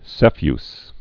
(sēfys, -fē-əs, sĕfē-)